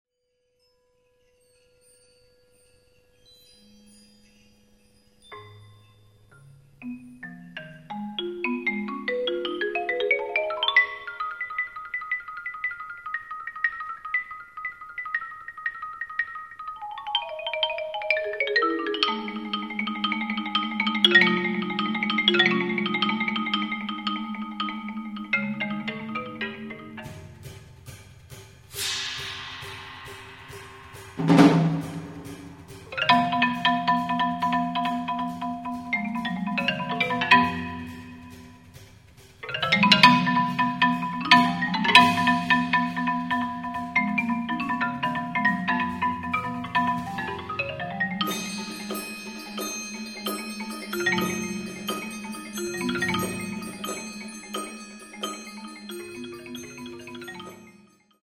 Voicing: Marimba